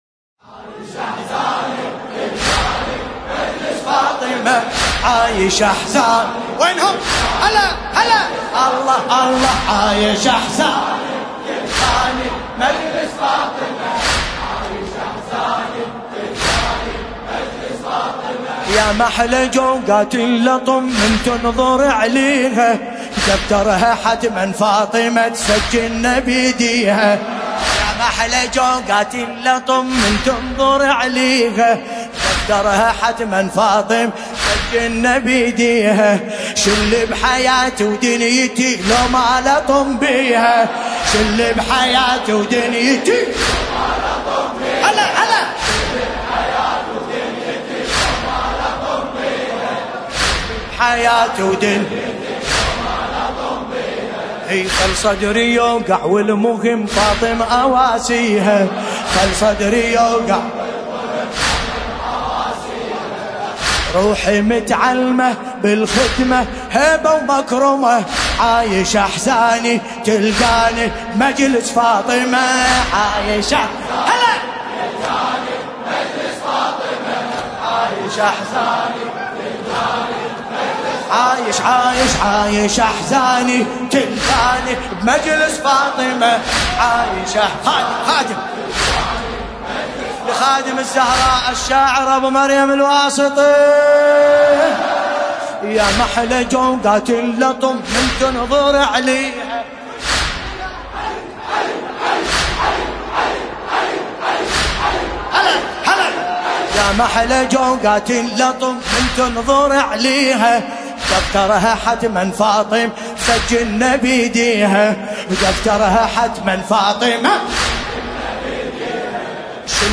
ملف صوتی عايش احزاني بصوت باسم الكربلائي
الرادود : الحاج ملا باسم الكربلائی المناسبة : الليالي الفاطمية 1440جامع الحاج علي باش - بغداد - الكريعات